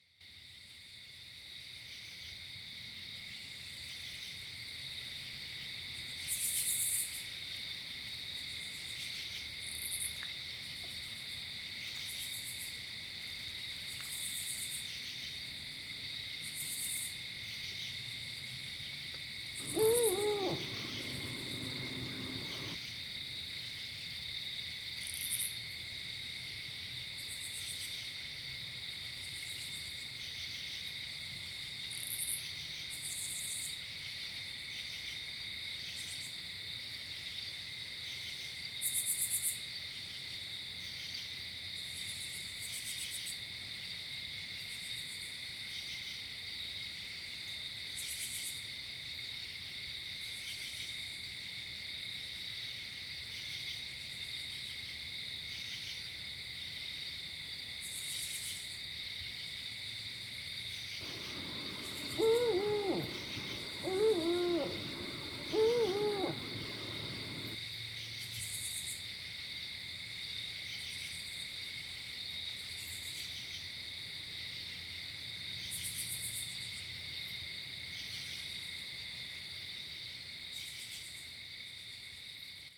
Dark_Wood_ambient.ogg